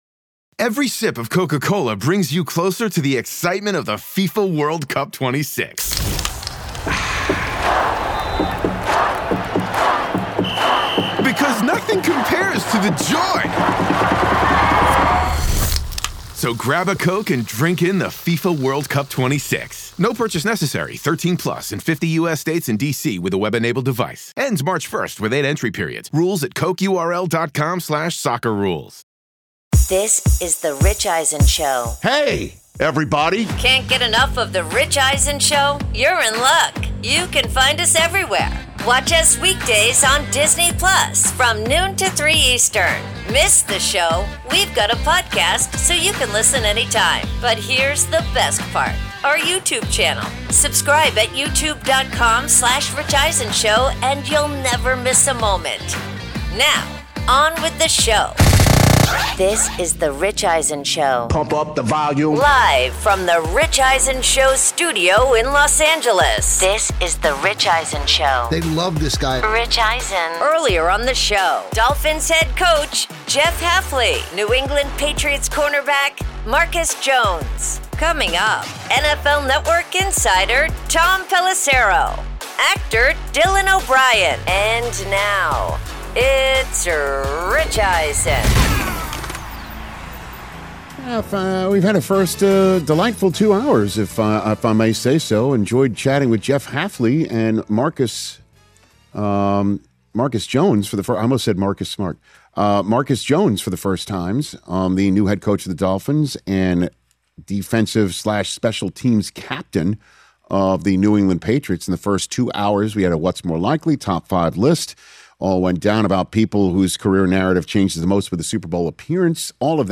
Hour 3: NFL Insider Tom Pelissero Talks Coaching Carousel, plus Actor Dylan O’Brien In-Studio Podcast with Rich Eisen